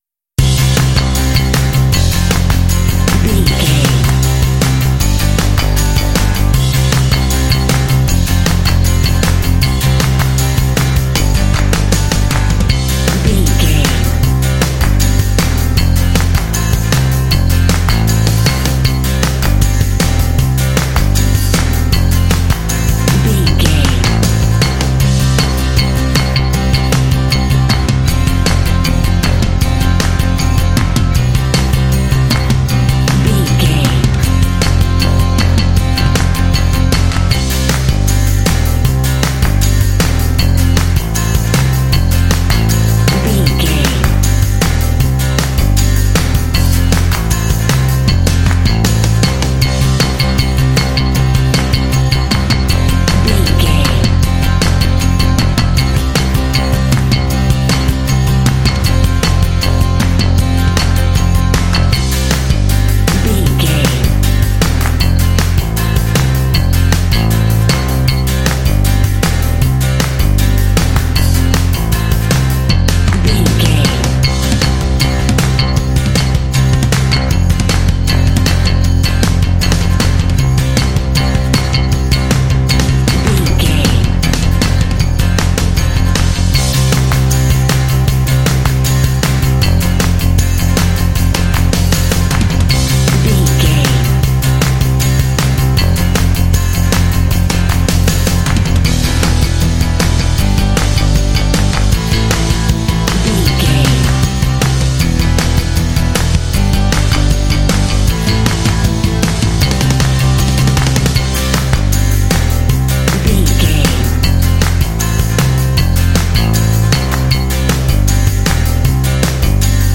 This lively rock track is ideal for action and sports games.
Uplifting
Ionian/Major
Fast
driving
bouncy
energetic
lively
bass guitar
electric guitar
drums
percussion
classic rock